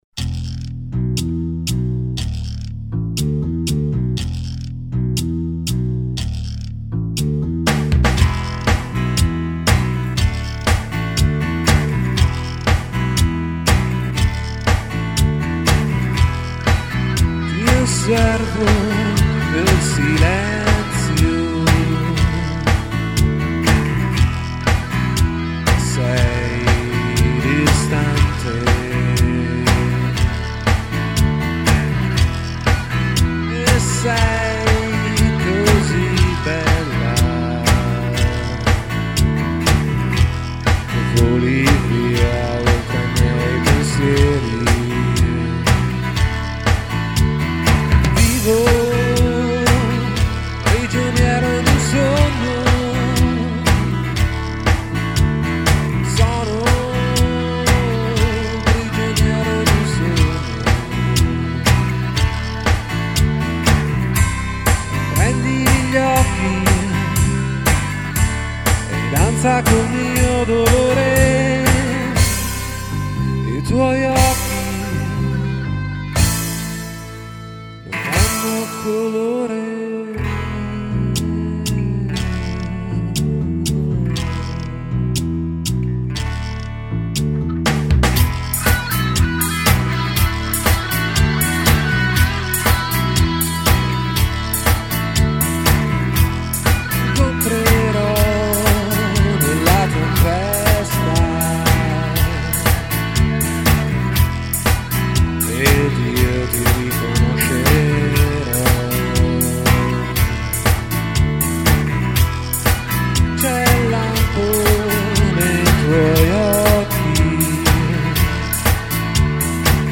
Chitarra acustica
Chitarra solista